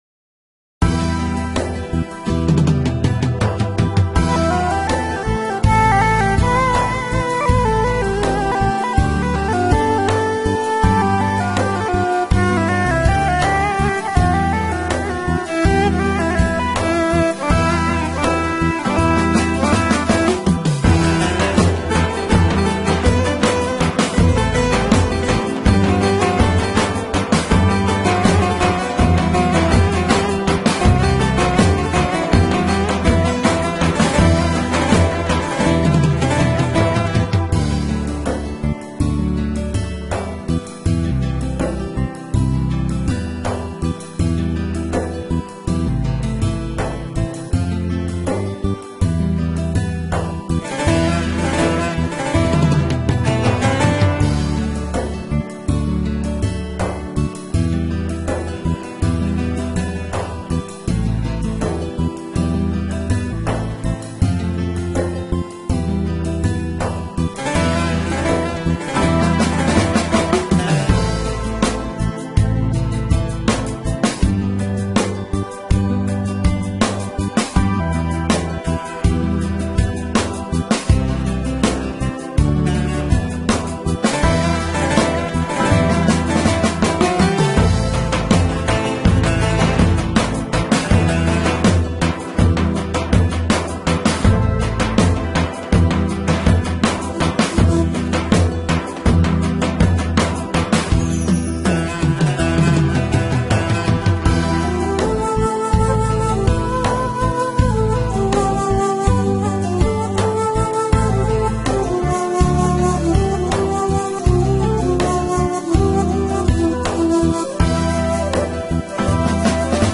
thm: atam dedim atılmıyor